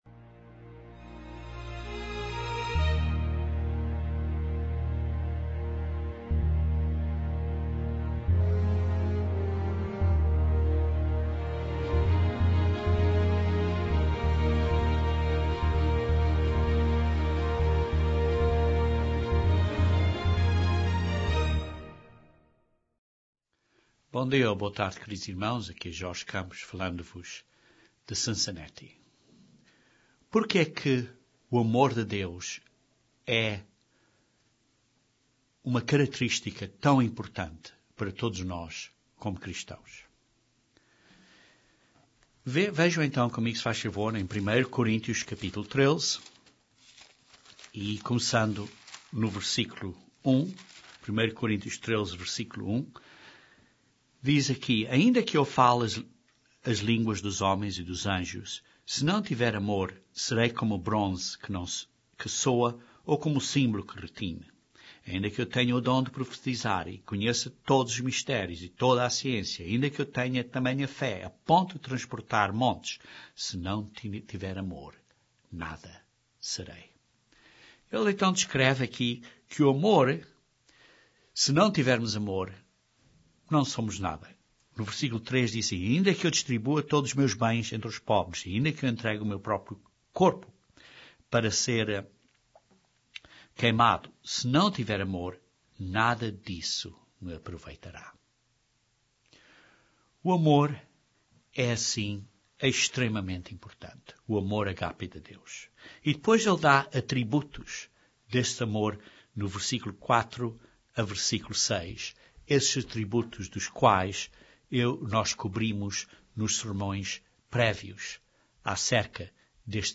Então por quê que o amor é o maior? Ouça esta razão neste último sermão desta série sobre o amor (agape) de Deus.